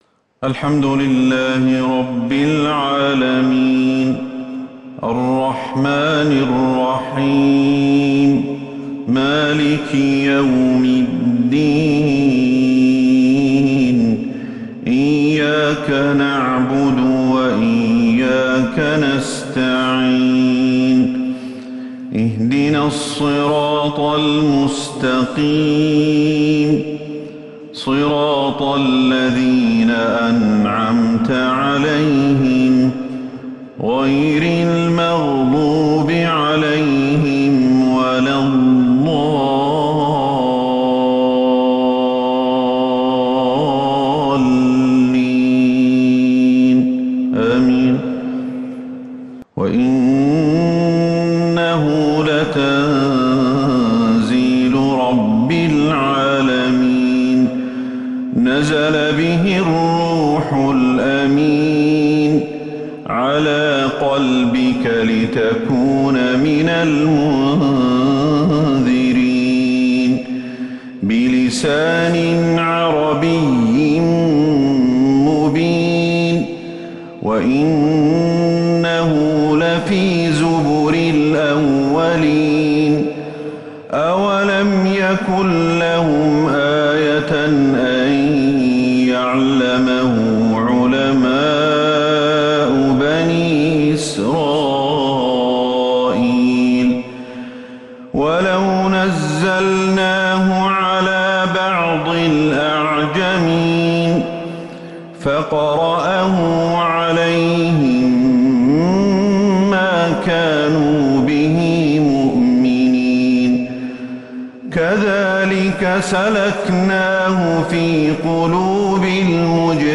عشاء السبت 10 ربيع الأول 1443هـ آواخر سورة {الشعراء} > 1443 هـ > الفروض